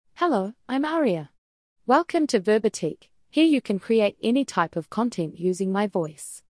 AriaFemale New Zealand English AI voice
Aria is a female AI voice for New Zealand English.
Voice sample
Listen to Aria's female New Zealand English voice.
Aria delivers clear pronunciation with authentic New Zealand English intonation, making your content sound professionally produced.